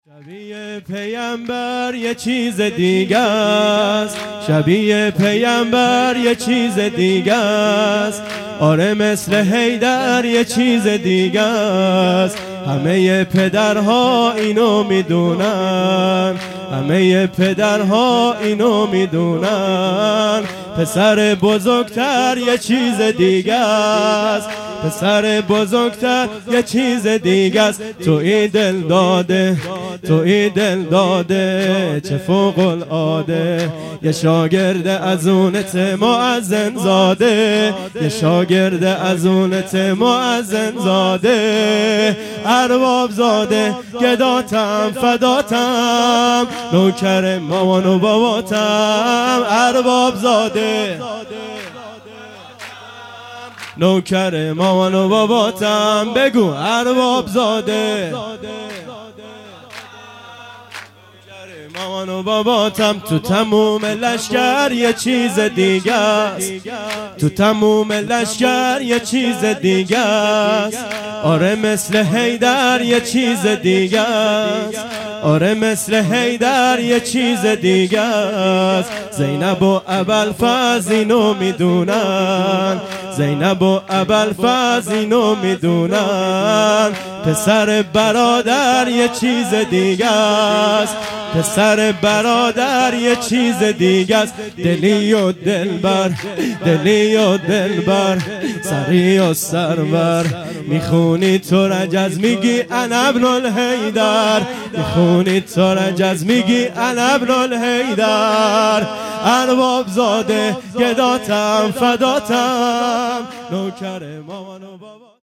سرود | مثل حیدر یه چیز دیگه است|بانوای گرم